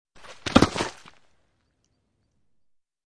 Descarga de Sonidos mp3 Gratis: caida 9.